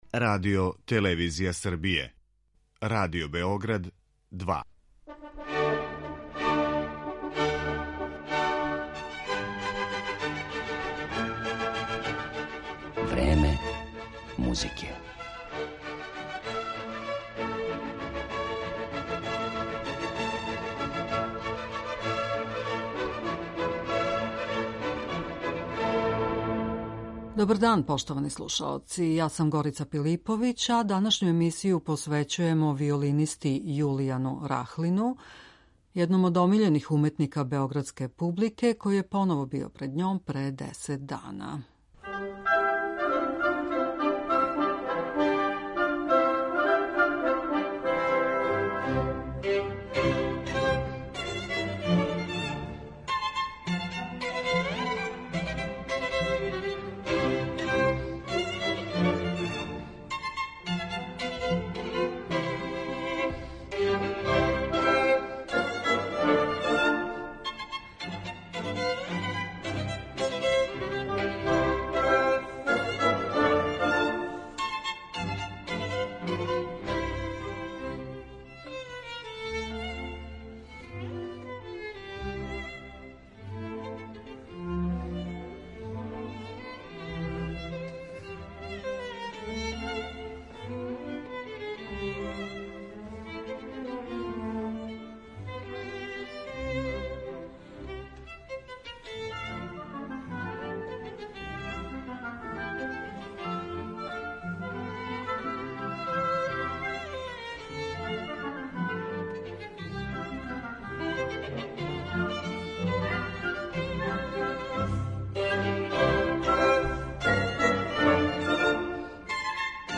Чућемо делове композиција које су том приликом извели, као и још Рахлинових снимака, уз његова размишљања на тему велике музичке традиције којој припада, значајних уметника из прошлости које је познавао, музичке педагогије и жеље да се бави дириговањем.